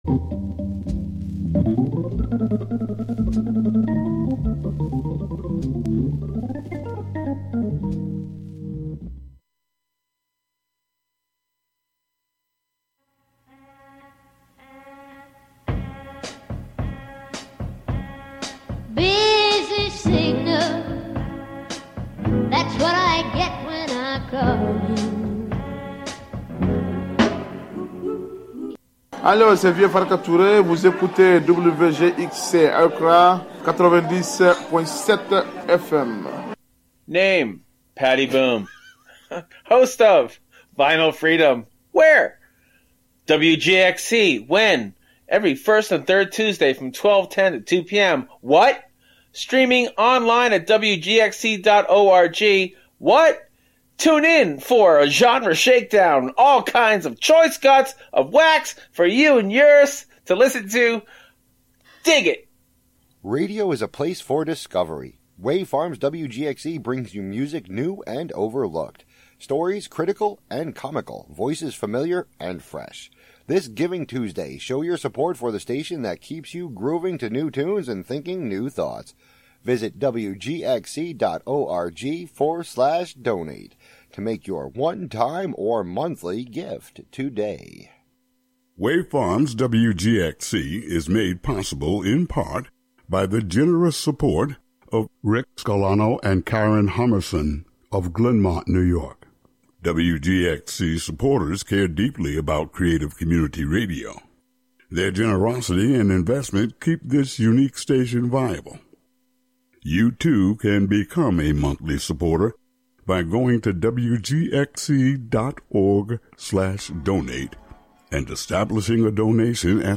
Hosted by various WGXC Volunteer Programmers.
During this very special hour, I am going to play for you the rarest record I own.
We will listen to this record, in community, and discuss.